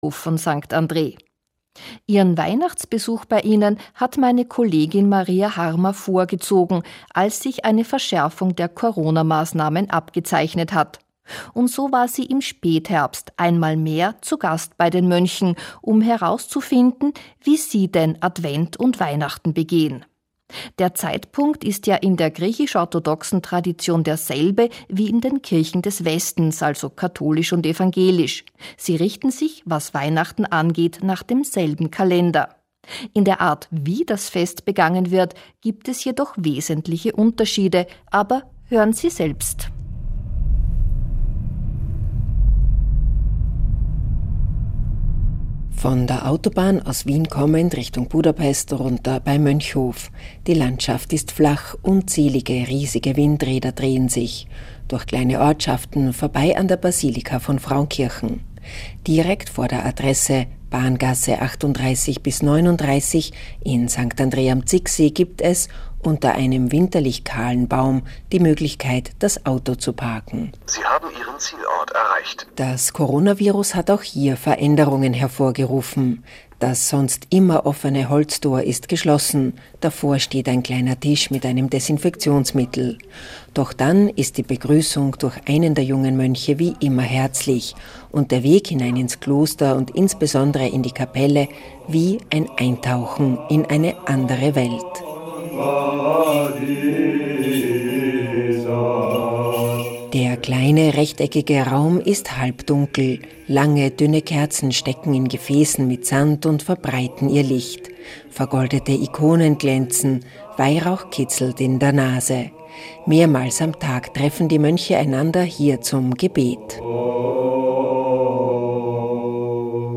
Es enthält einen Ö1-Radiobeitrag vom 25.12.2020. Sie können ihn unter diesem Link abrufen.